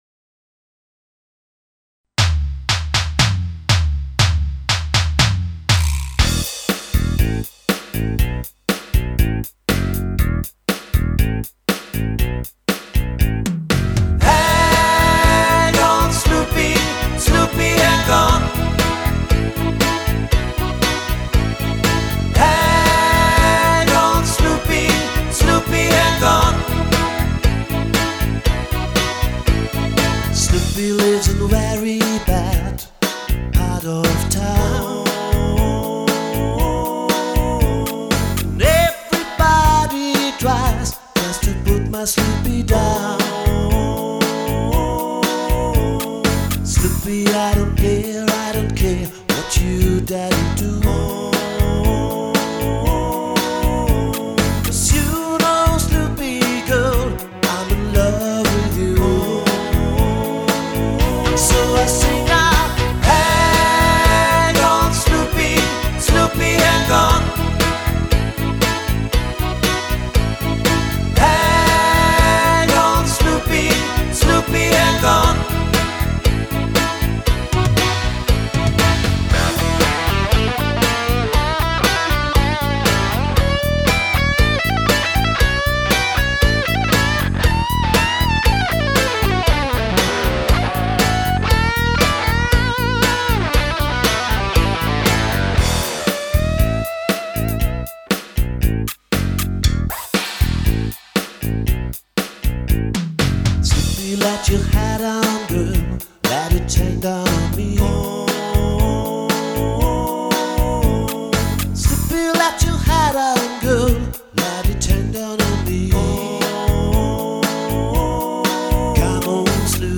Tanzband aus Monzingen
• Coverband